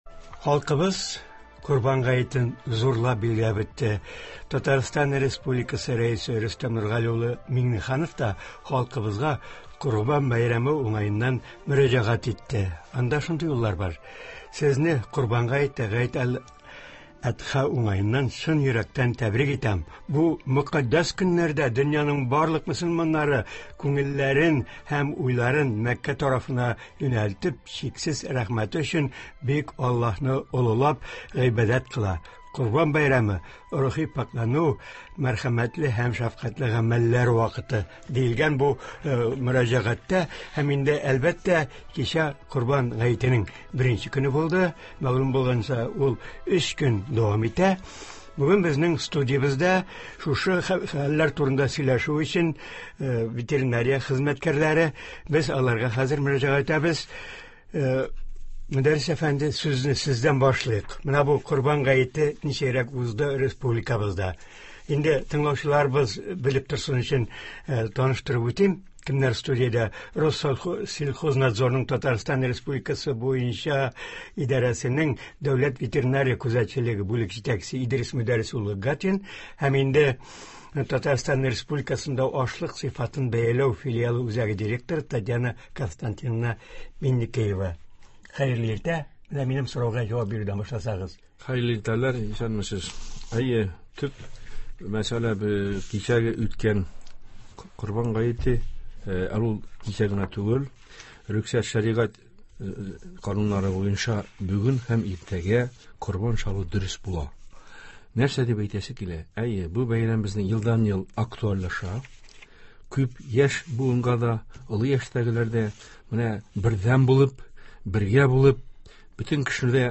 тыңлаучылар сорауларына җавап бирәчәкләр.